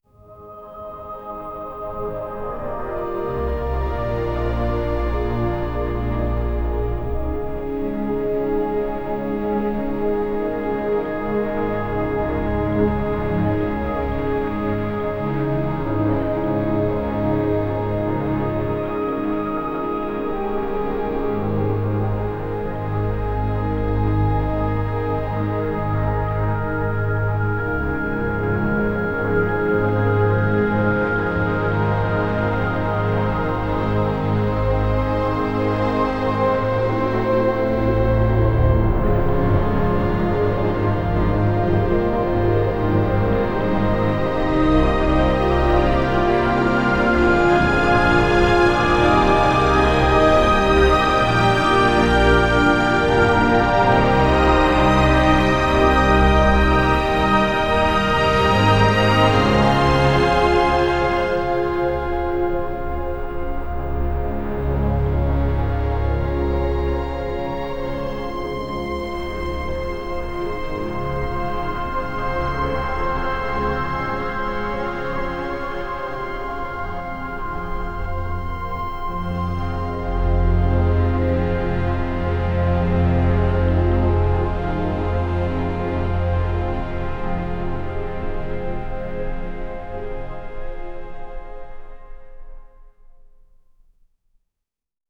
ChoirChordsBassMaster_4.wav